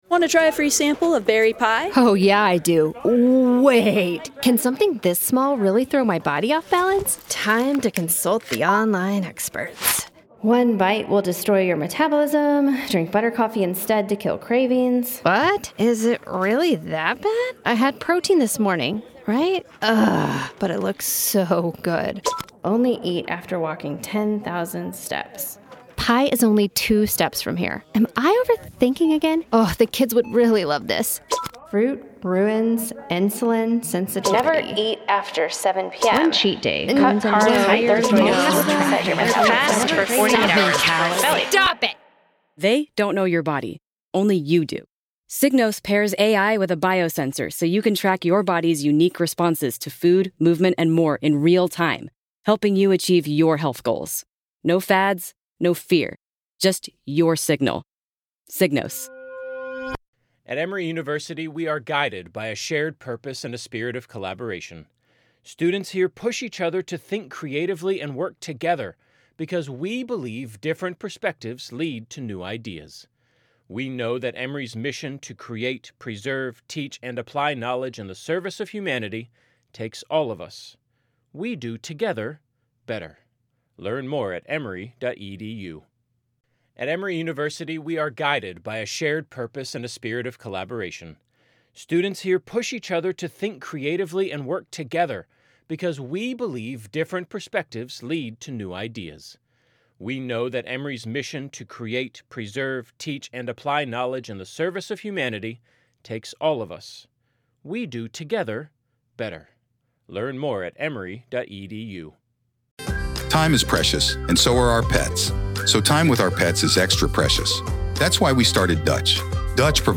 RAW COURT AUDIO-Karen Read: Post-Jury Selection Court Hearing-PART 1